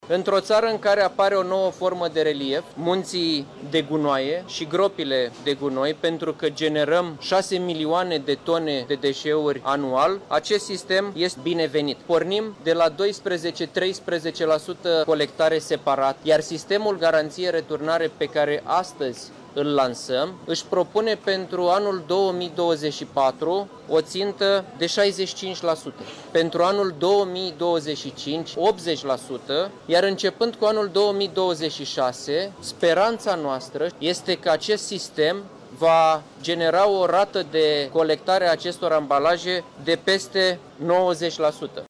Ministrul Mediului, Mircea Fechet, a spus câte deșeuri va reuși România să colecteze prin acest nou sistem.